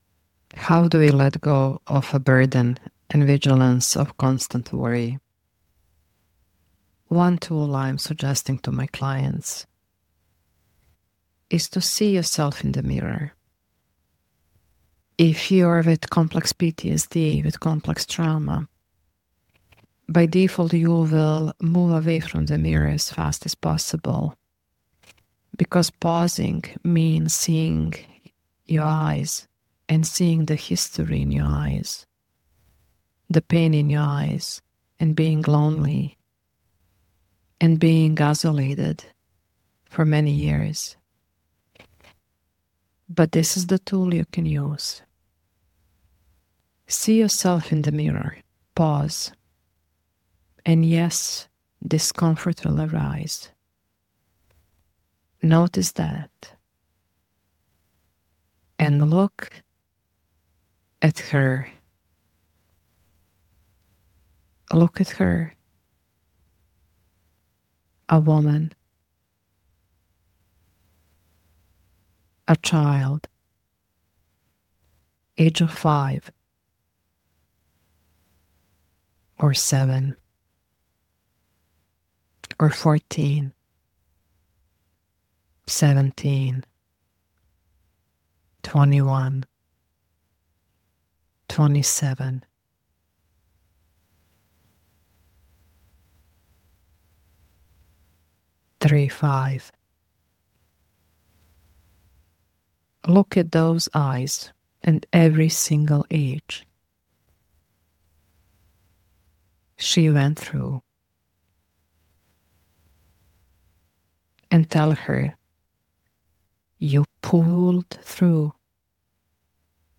It is a sacred pause—a slow, intentional, and embodied return to self.
Tone & Emotional Atmosphere